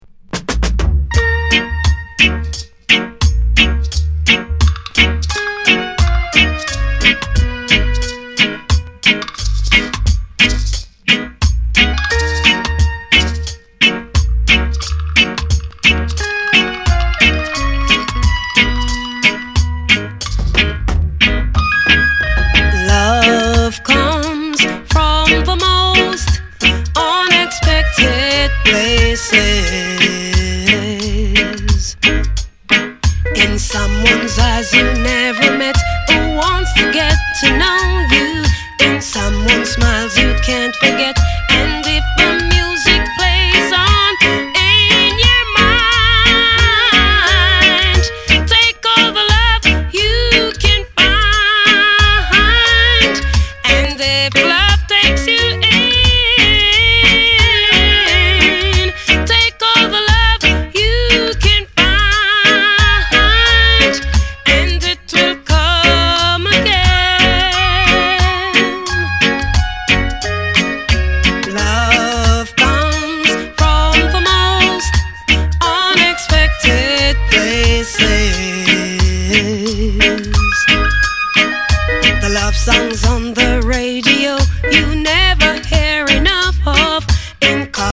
スウィートな中にも熱さが光る素晴らしい内容です。